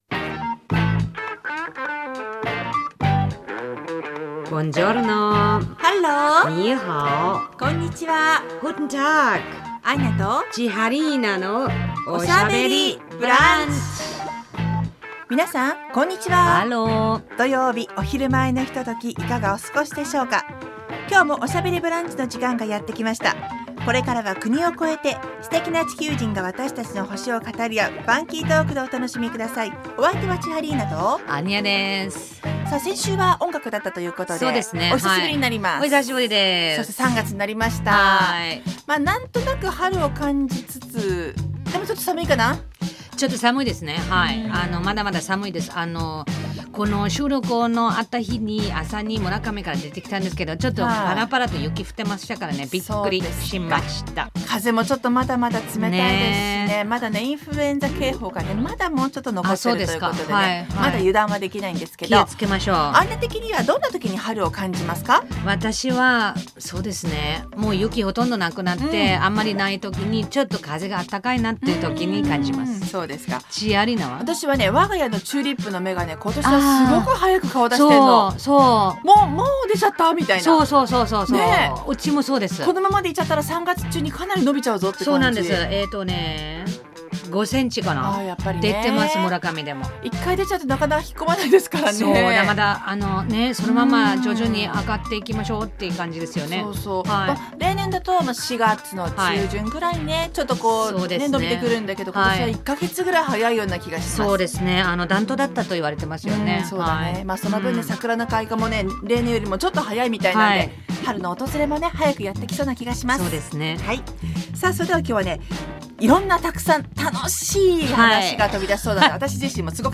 放送された内容を一部編集してお送りします。